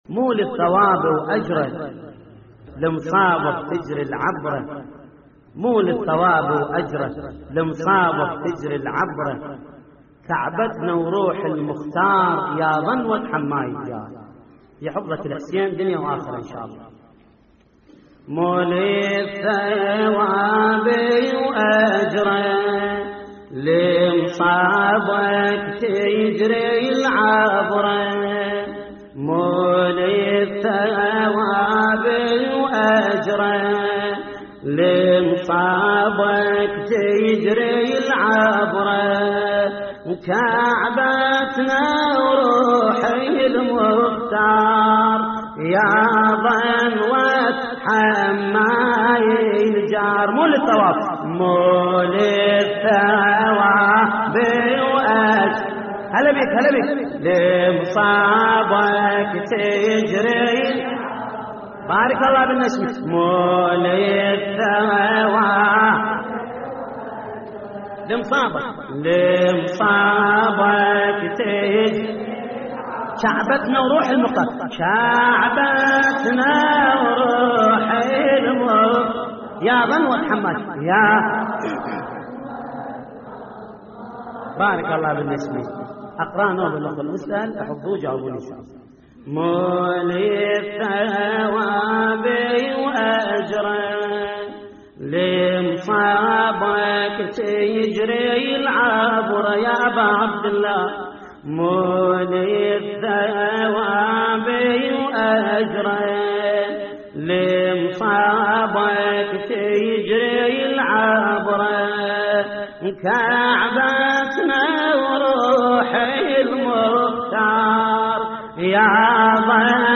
تحميل : مو للثواب وأجره لمصابك تجري العبره / الرادود جليل الكربلائي / اللطميات الحسينية / موقع يا حسين